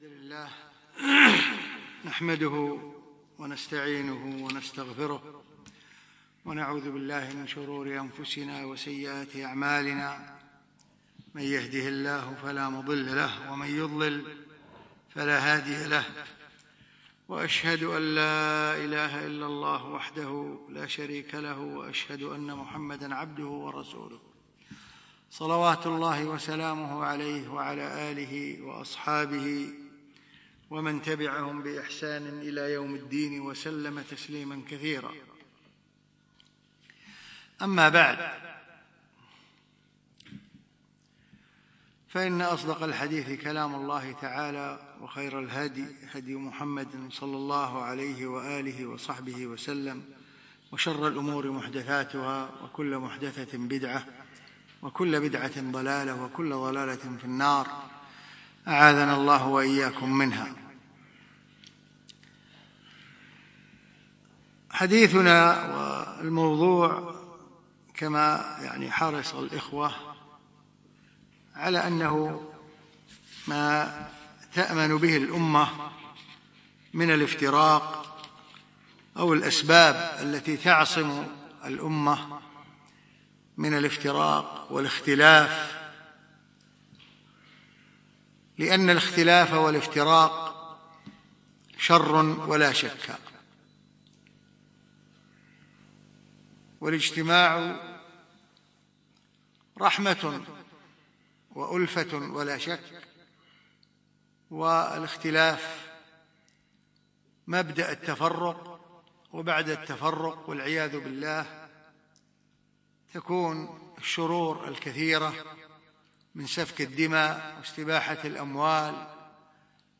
الخميس 29 جمادى الأخر 1437 الموافق 7 4 2016 في مسجد مضحي الكليب العارضية